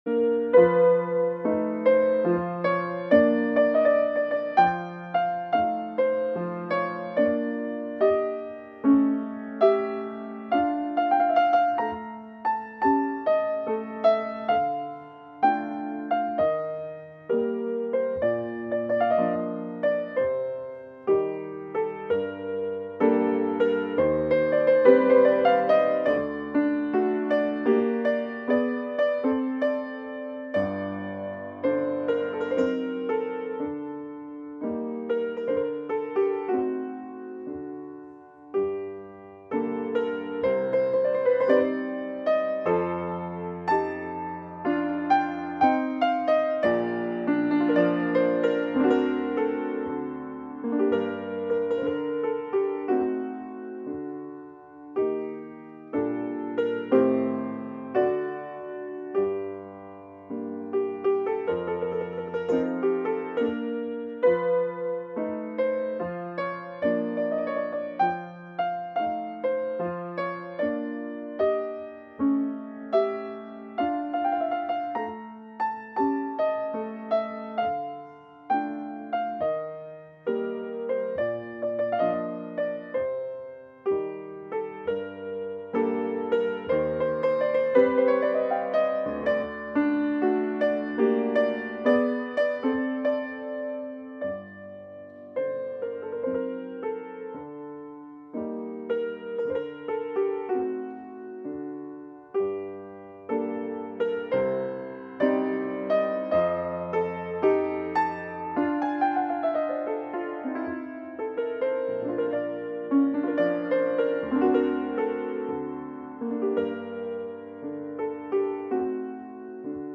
LIVE Evening Worship Service - Remember
Congregational singing—of both traditional hymns and newer ones—is typically supported by our pipe organ.